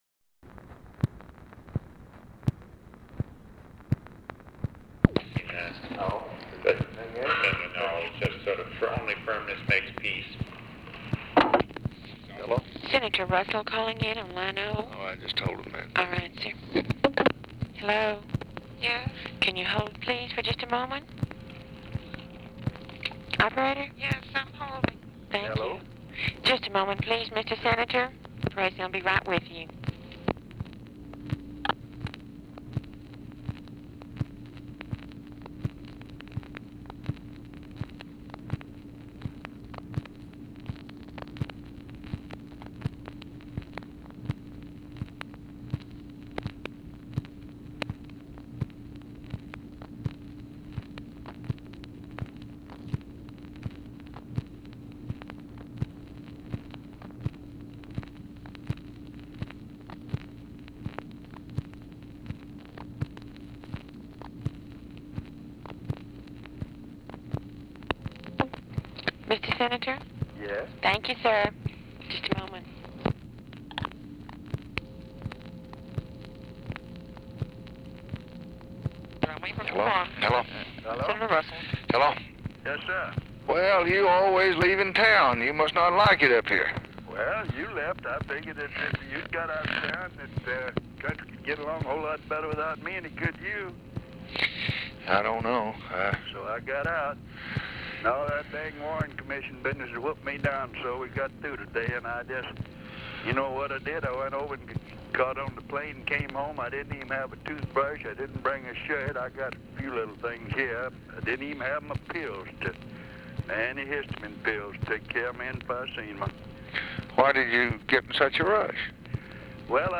Conversation with RICHARD RUSSELL and OFFICE CONVERSATION, September 18, 1964
Secret White House Tapes